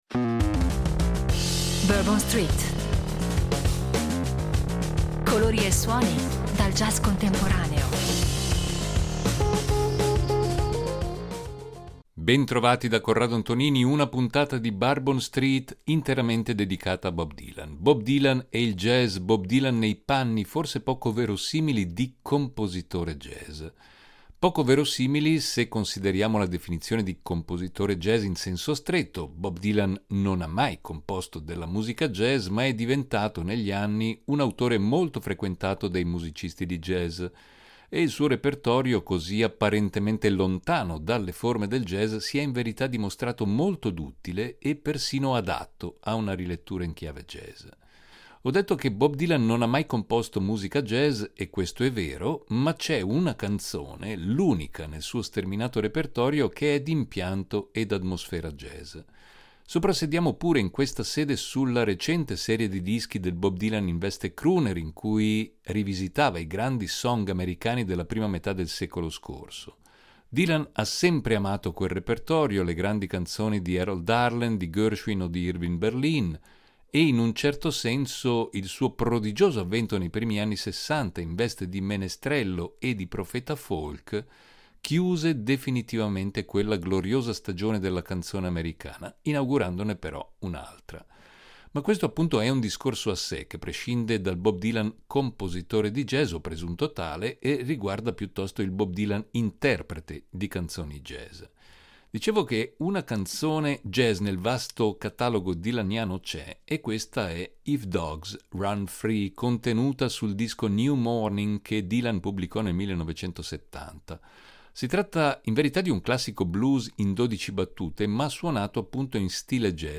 Dalla sua musica folk, un’ispirazione per il jazz
in chiave jazz